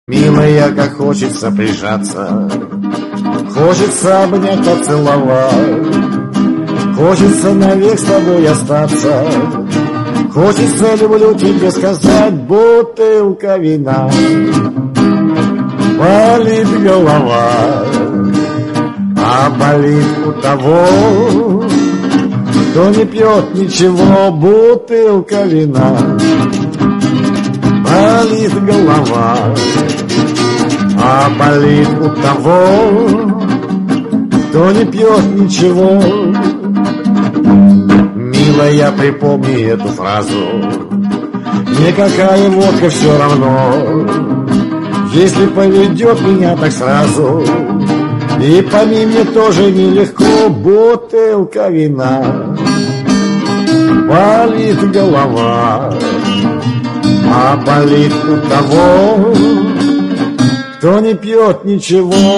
блатные